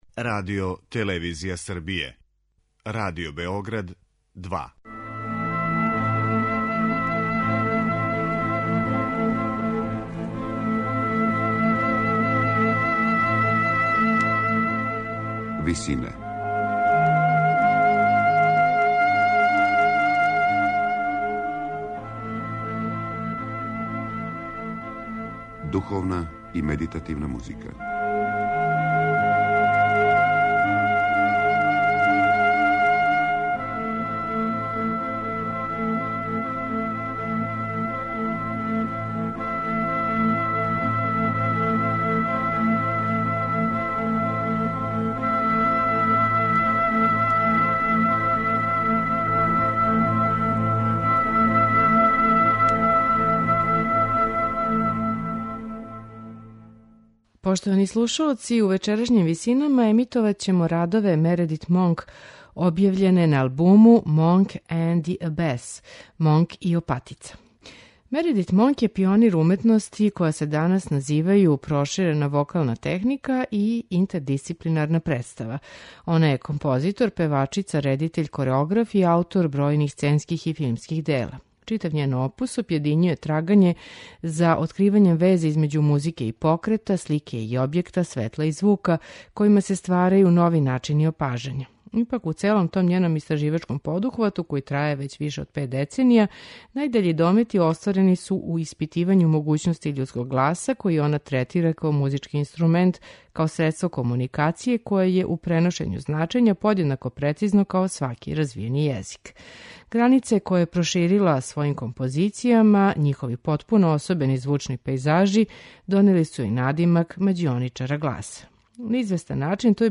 за вокални ансамбл
медитативне и духовне композиције